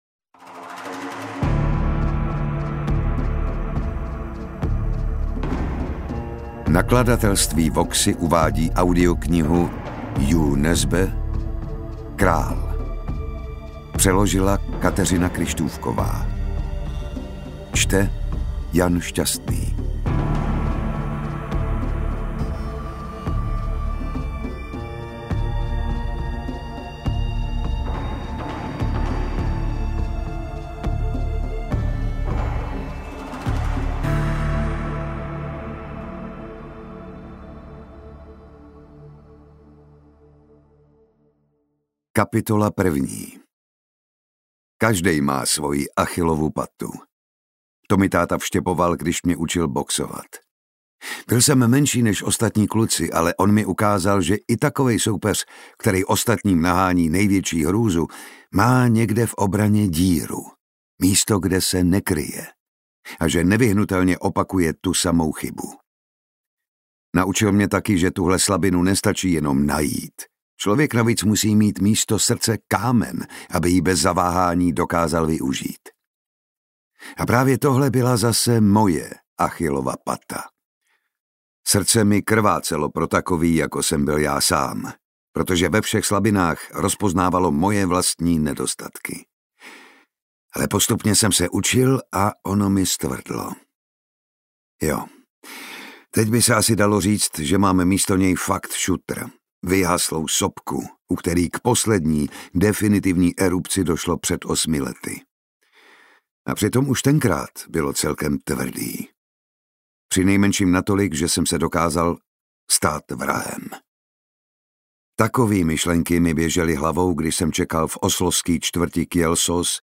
Interpret:  Jan Šťastný
AudioKniha ke stažení, 46 x mp3, délka 14 hod. 44 min., velikost 805,2 MB, česky